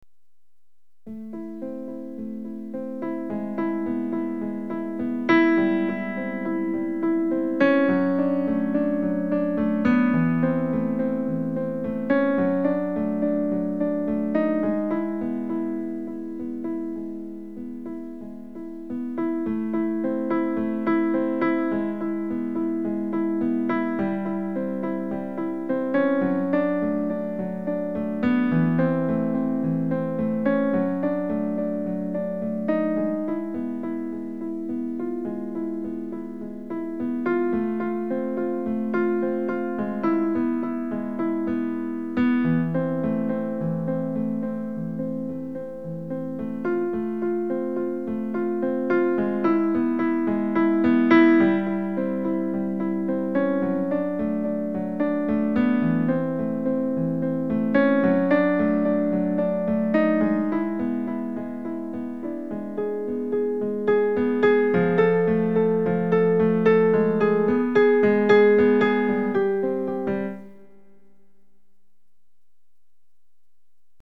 Well we agreed we would do one instrumental song only.
well, we have music that is in the sort of sadness atmosphere.
lol, we don't have one its only on piano + guitar...
The 'go up' part is improvisation, because staying low makes it 'monotone' (boring)... so i am finding a way to get a bit more diversity.
I can have a go at doing some drums for it, if you want... it definatly needs some sort of rhythmic support...